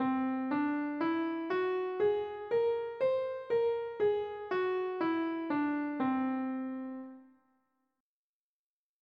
Whole-Tone Scales
C4 – D4 – E4 – F#4 – G#4 – A#4 – C5
In contrast to the chromatic scale, the whole-tone scale is entirely built on whole steps (or a staircase containing only large steps), resulting in a shorter scale length than the major, minor, and chromatic scales.
C-whole-tone-scale.mp3